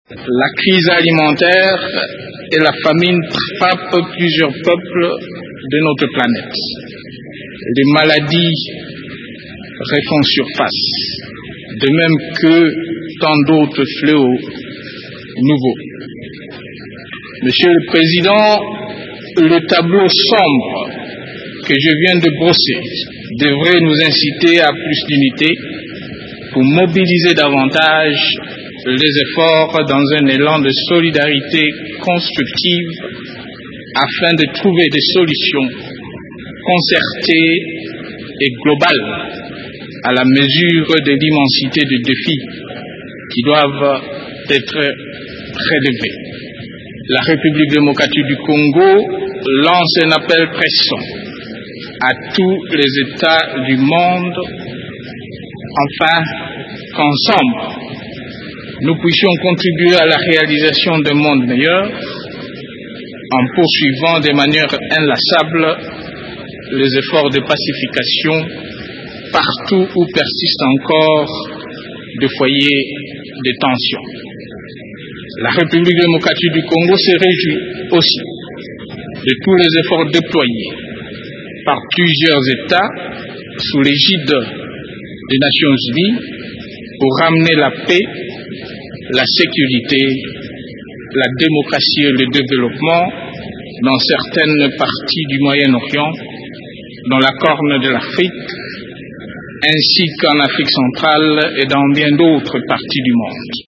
Le président de la République démocratique du Congo, Joseph  Kabila s’est exprimé, jeudi 23 septembre, à la  tribune des  Nations  Unies à l’occasion de la 65è session ordinaire de l’Assemblée générale.
Extrait de l’allocution de Joseph  Kabila